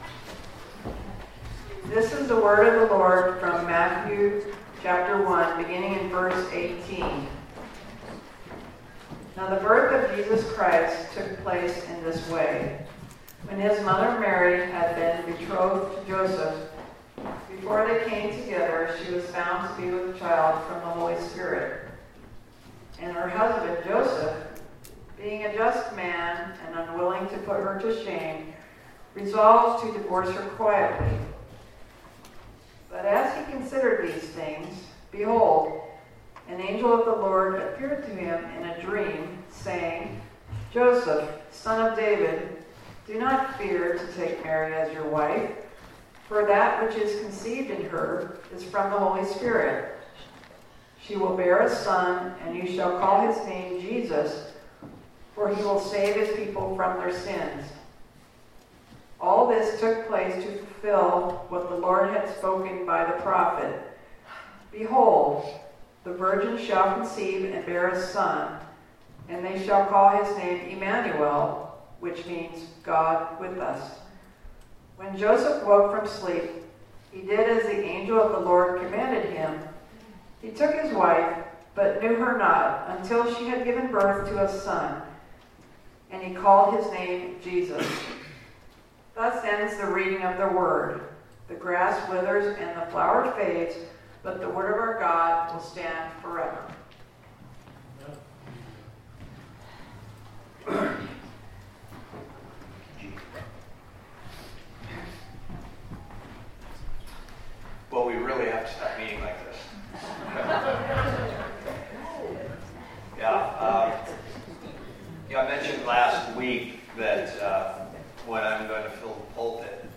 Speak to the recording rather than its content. Service Type: Sunday Morning Topics: betrothal problem , hearing the message , obedience « The Shepherds